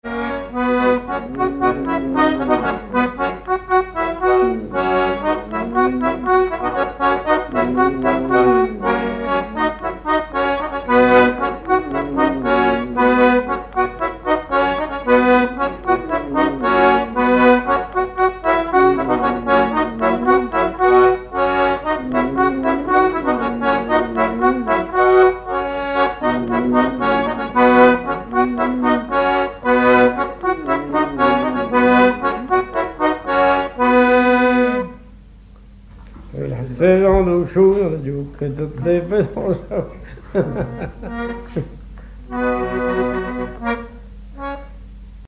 Couplets à danser
branle : courante, maraîchine
Répertoire sur accordéon diatonique
Pièce musicale inédite